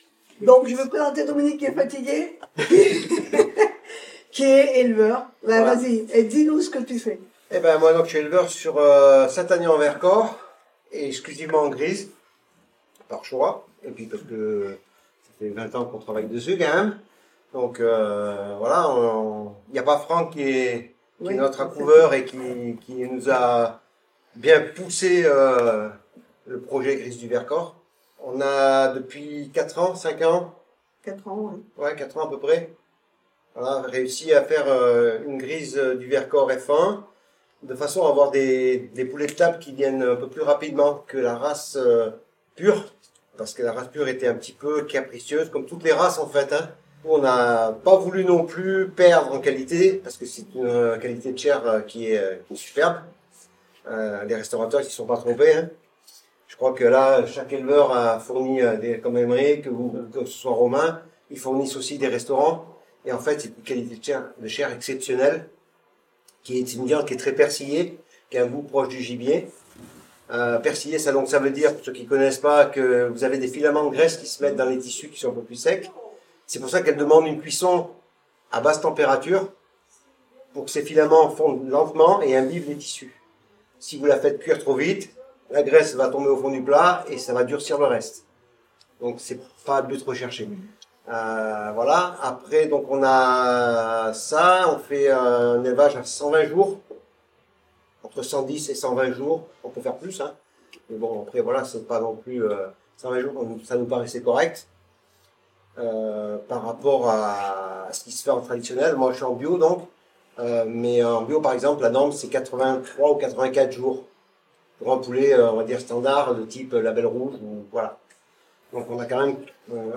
TABLE RONDE – POURQUOI ÉLEVER LA GRISE DU VERCORS?